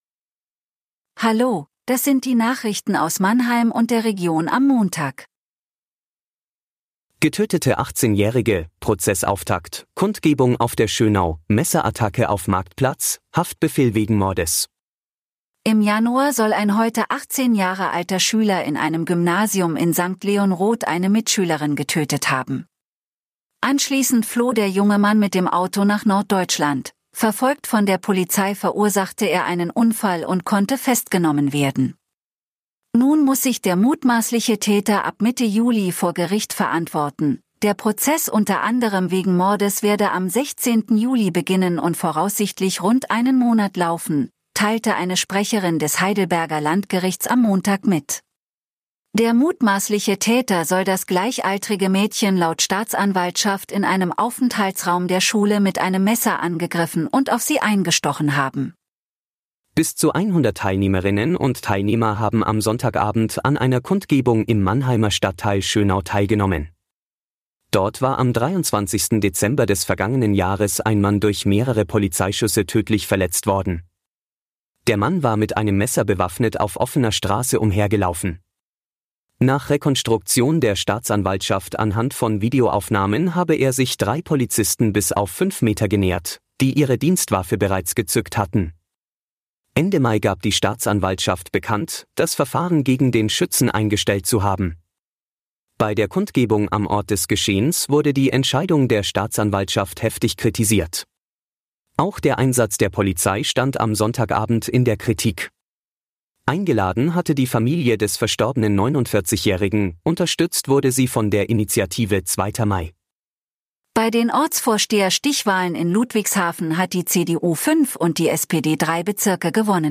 Der Nachrichten-Podcast des MANNHEIMER MORGEN